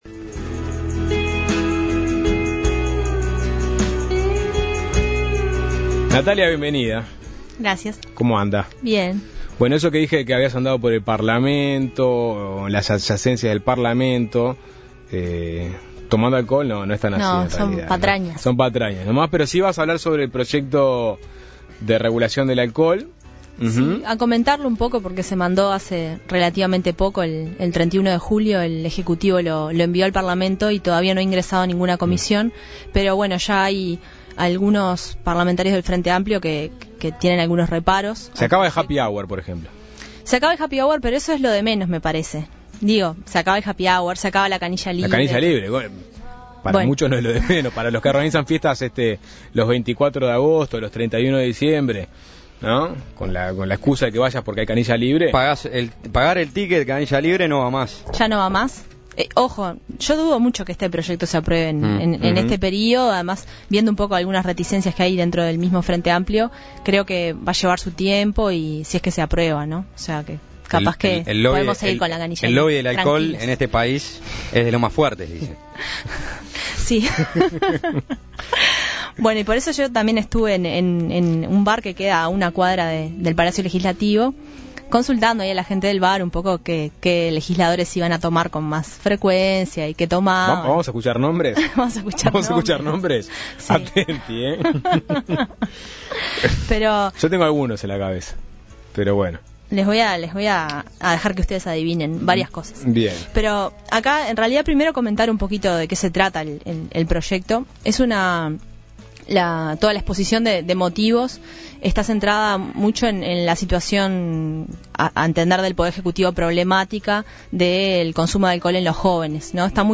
Además, recogió las impresiones del senador nacionalista Carlos Moreira y el diputado del MPP Alejandro Sánchez sobre el contenido del proyecto de ley.